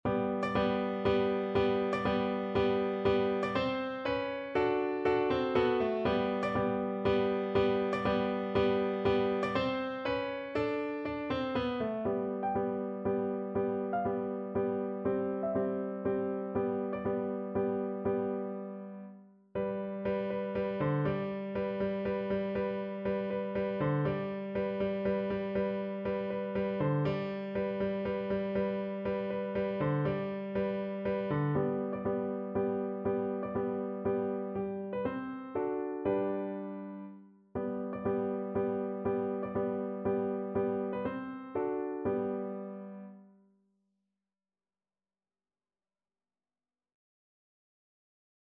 Piano version
3/4 (View more 3/4 Music)
Moderato =120
Classical (View more Classical Piano Music)